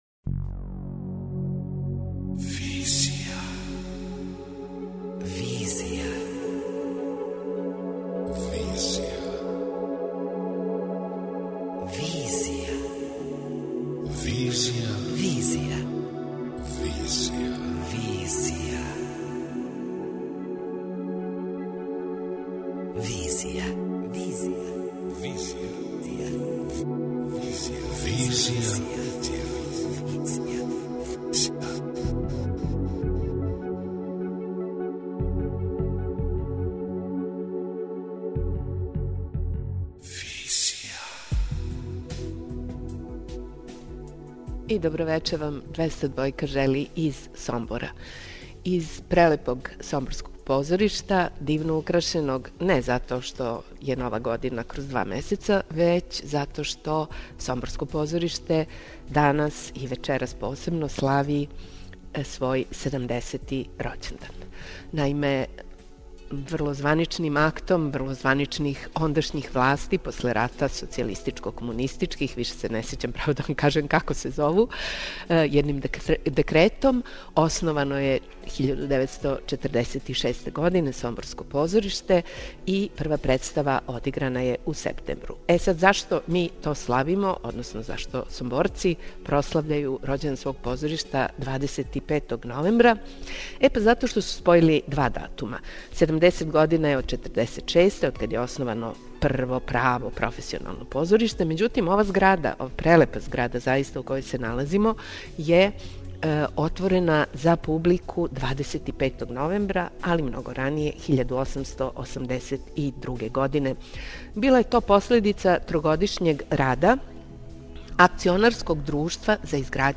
Седамдесети рођендан Народно позориште Сомбор прославља вечерас, свечаним програмом од 20 сати, док ће са исте те позорнице, од 17 сати, почети наше специјално издање Арса и Визије. Угостићемо редитеље и глумце који су градили и граде ово значајно српско позориште.